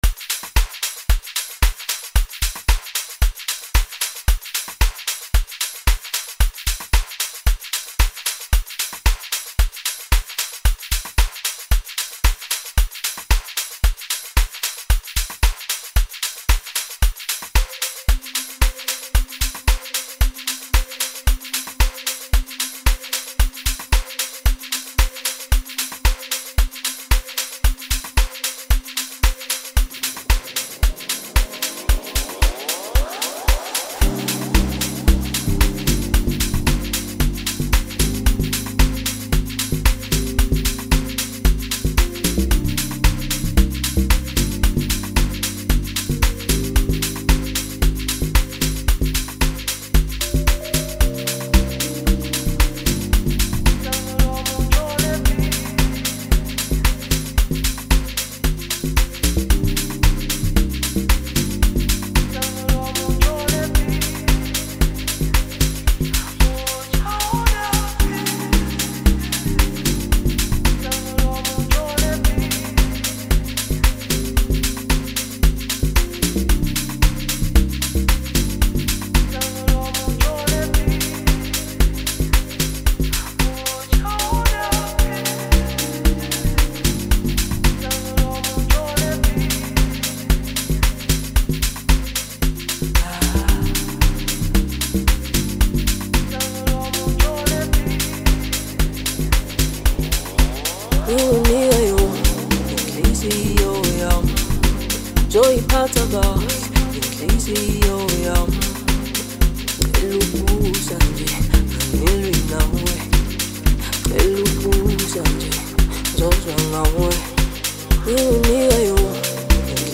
Home » Amapiano » DJ Mix » Hip Hop